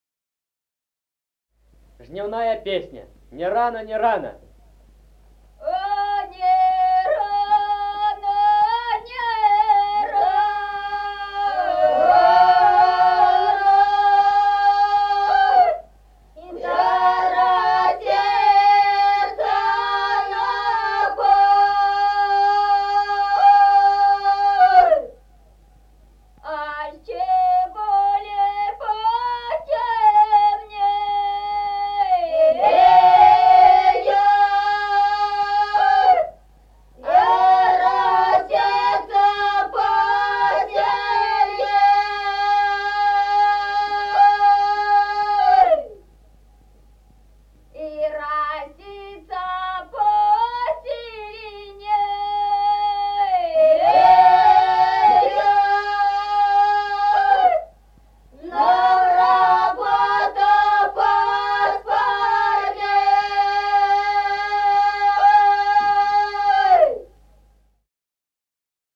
Народные песни Стародубского района «А не рано, не рано», жнивная.
(подголосник)
(запев).
1953 г., с. Мишковка.